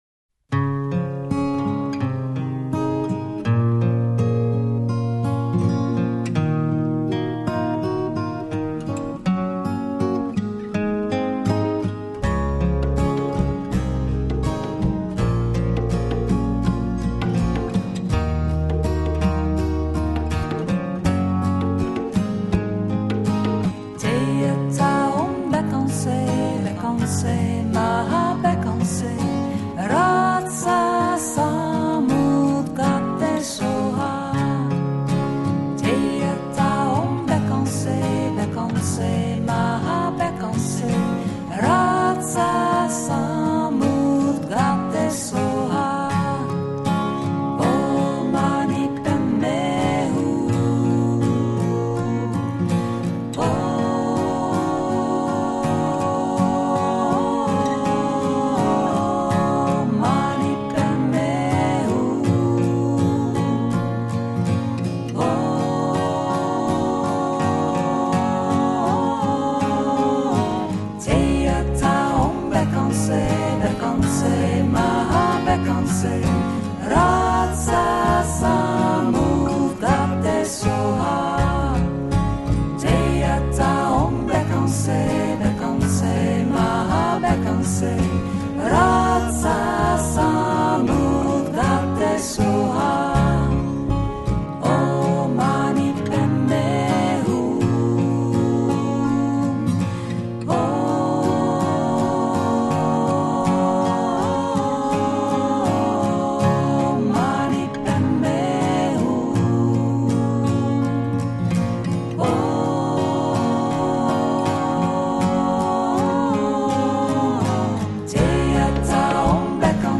Genre: New Age / Meditative / Mantras